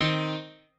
piano3_29.ogg